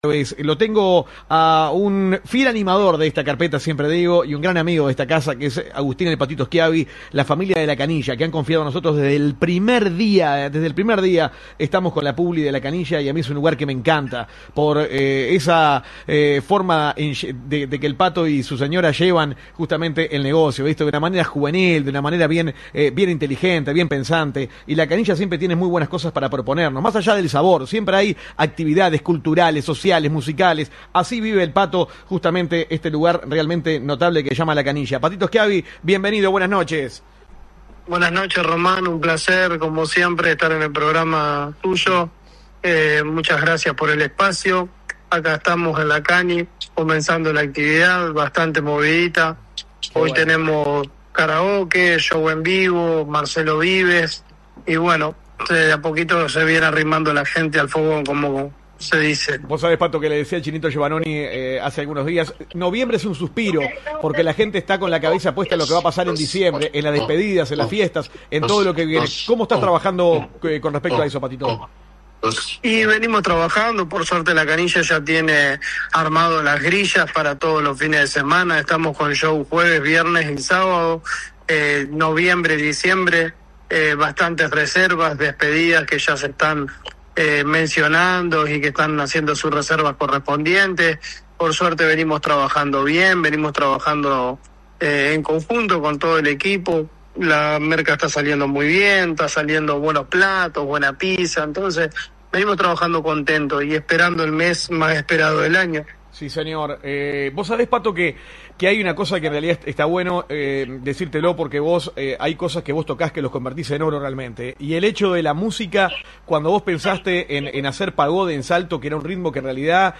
Comunicación telefónica con